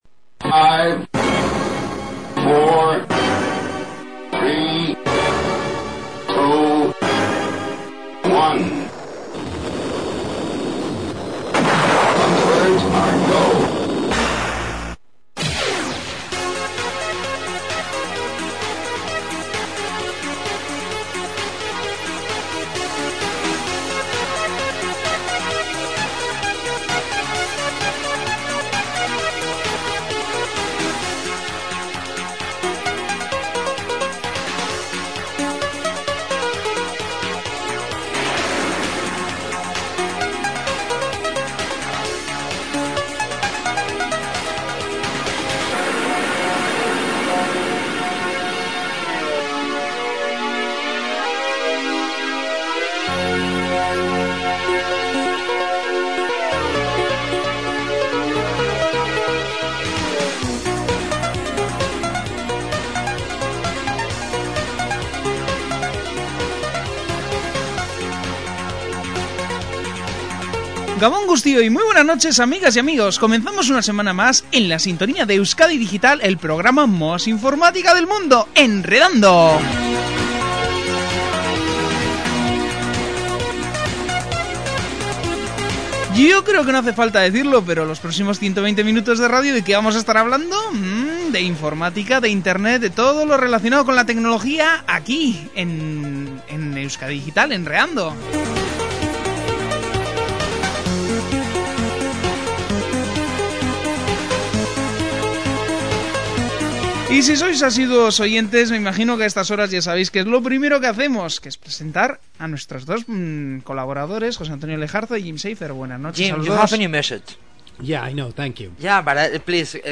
En el episodio 12 de Enredando, emitido en la sintonía de Euskadi Digital, el equipo celebra el final de 2002 con un programa especial de Navidad lleno de humor y reflexión.
Entre los temas comentados, se incluye la controversia sobre software para copiar DVDs, como el DVD X Copy, y su legalidad bajo el concepto de «uso legítimo» en Estados Unidos. Los presentadores también discuten los recientes agujeros de seguridad detectados en